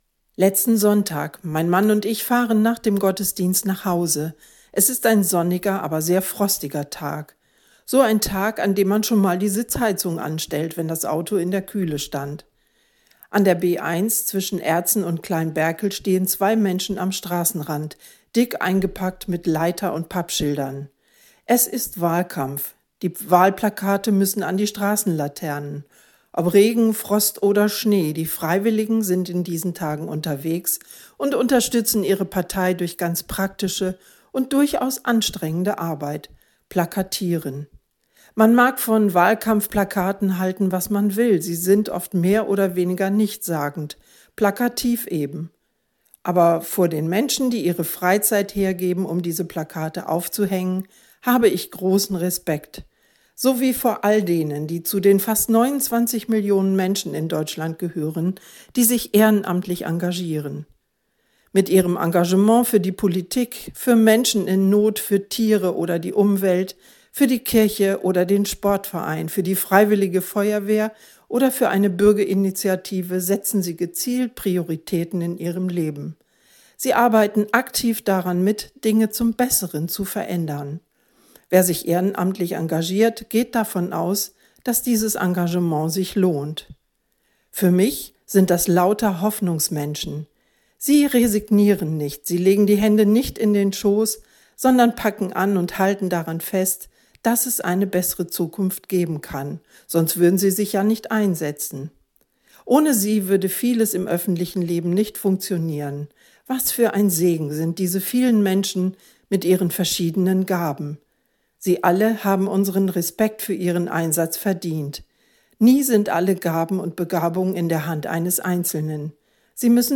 Radioandacht vom 16. Januar – radio aktiv
radioandacht-vom-16-januar.mp3